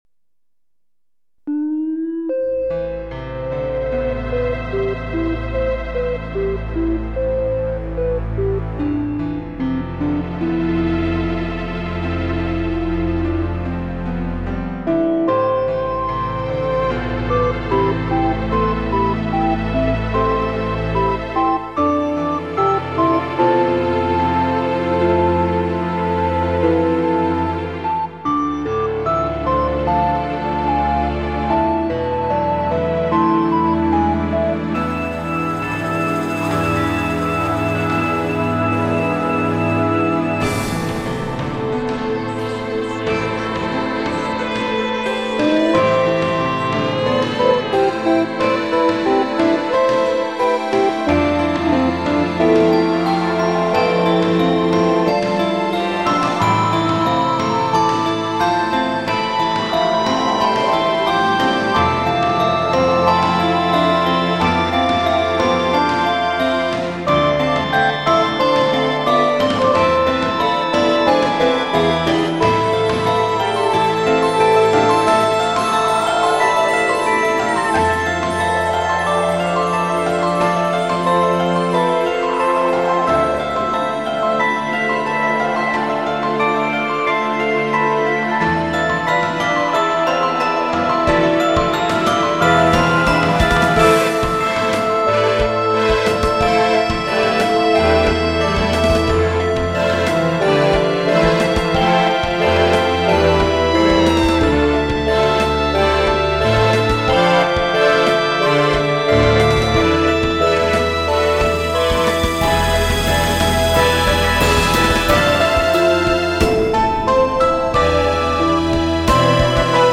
インストゥルメンタル